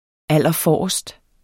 Udtale [ ˈalˀʌˈfɒˀɒsd ]